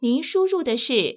ivr-you_entered.wav